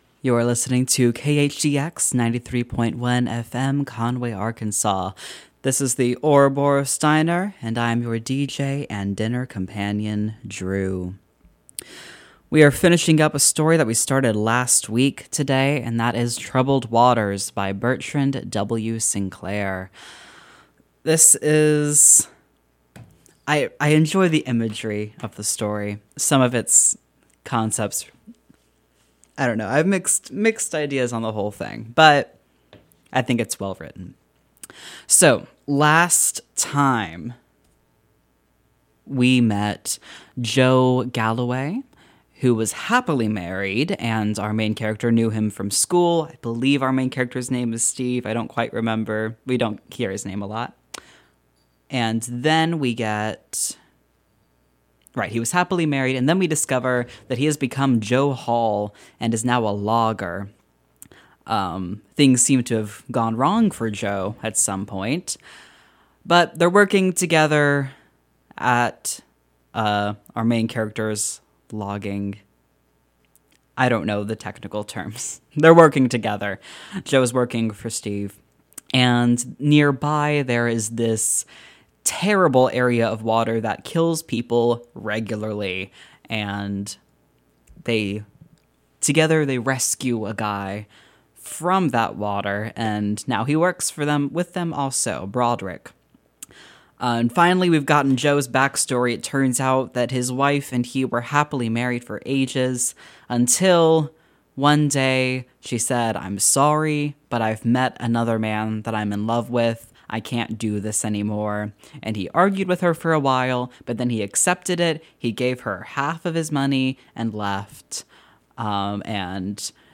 Today we finish “Troubled Waters” and listen to a little playlist of songs named after goddesses.
It’s always fun to throw together a mini-playlist, and we got a little experimental too.
As I explained, the title comes from one of Inanna’s epithets (though I briefly forgot the word), and I read from one of Enheduanna’s hymns to her.